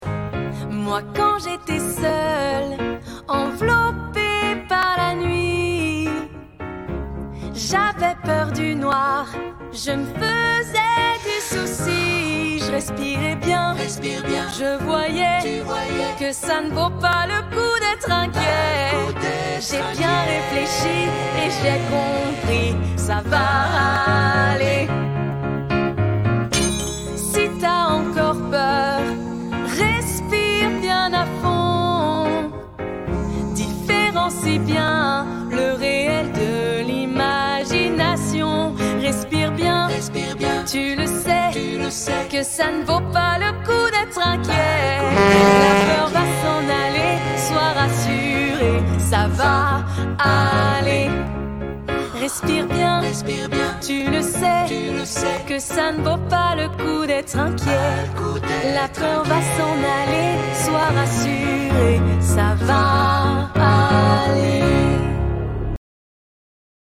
8 - 30 ans - Mezzo-soprano